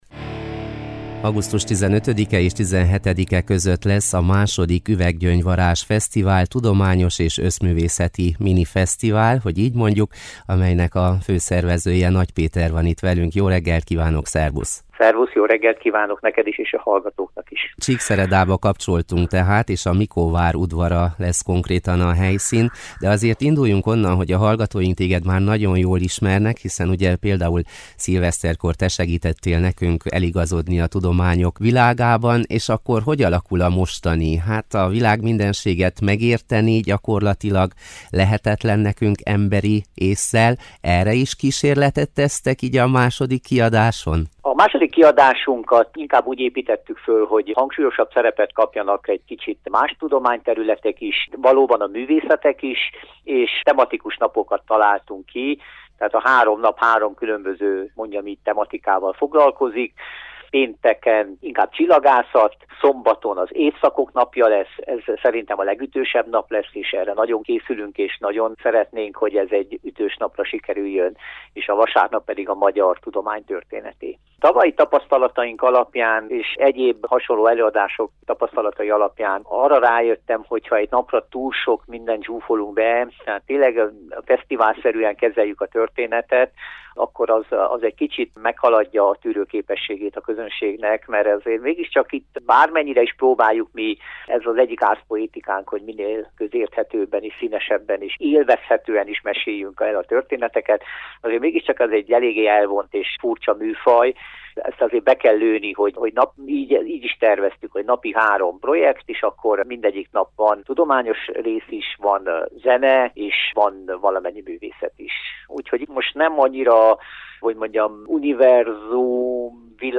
Beszélgetőtárs: